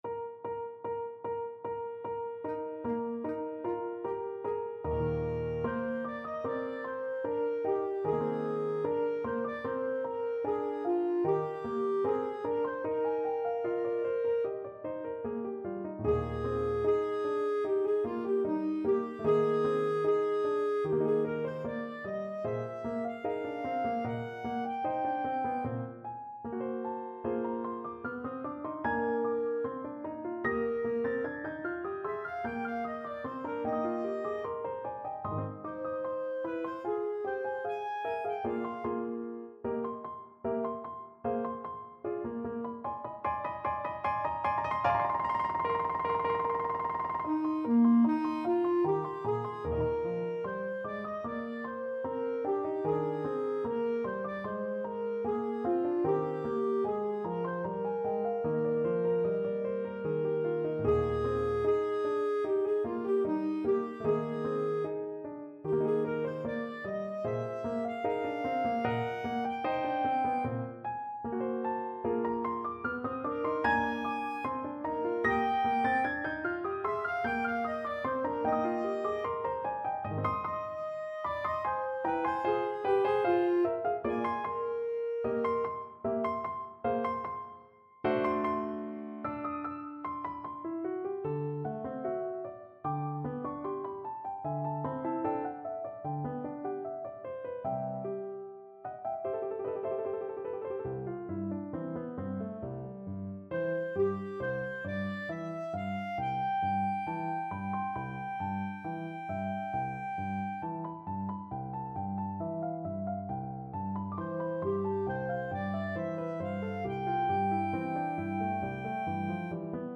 Classical
GemŠchlich (nicht eilen) =150
2/2 (View more 2/2 Music)
Arrangement for Clarinet and Piano